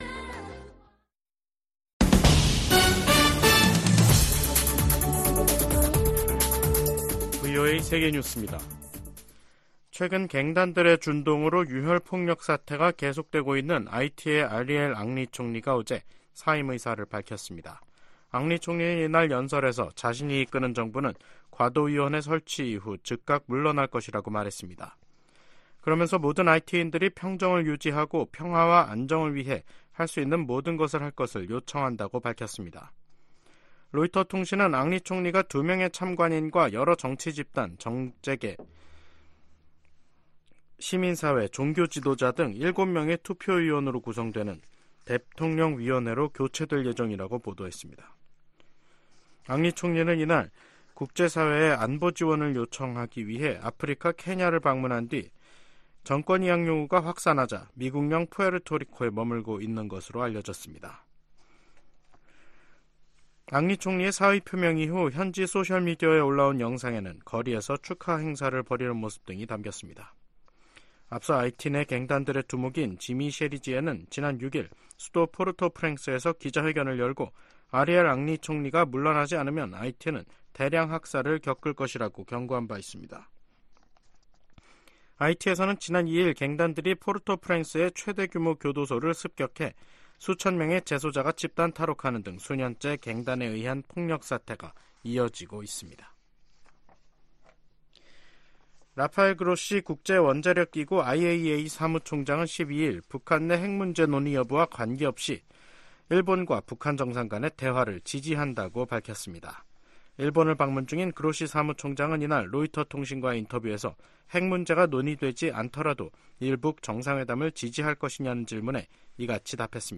VOA 한국어 간판 뉴스 프로그램 '뉴스 투데이', 2024년 3월 12일 3부 방송입니다. 북한과 중국, 러시아, 이란의 협력이 동맹 수준으로 발전하지는 못할 것이라고 미 국가정보국장이 내다봤습니다. 탈북민 구조활동을 벌여 온 것으로 알려진 한국 선교사가 올해 초 러시아 당국에 간첩 혐의로 체포됐습니다. 존 볼튼 전 미 국가안보보좌관은 도널드 트럼프 전 대통령이 재집권할 경우 미북 정상회담이 다시 추진될 수도 있을 것으로 VOA 인터뷰에서 전망했습니다.